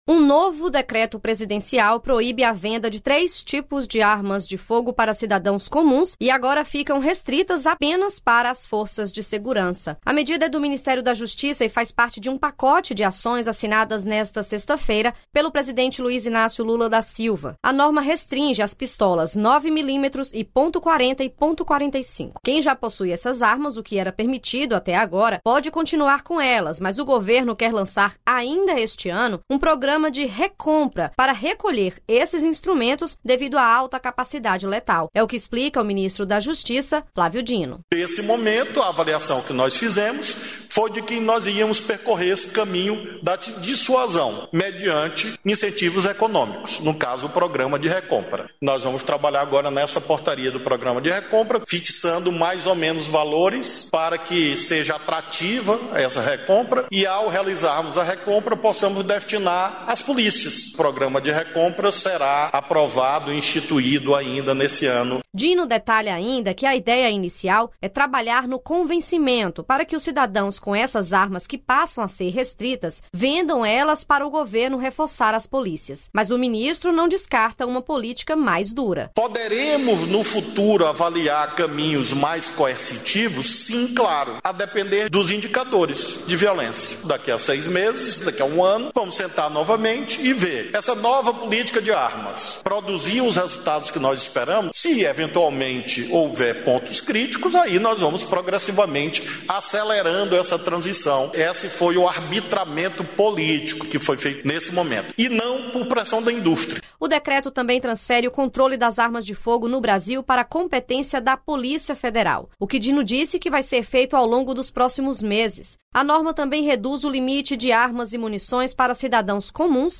É o que explica o ministro da Justiça, Flávio Dino.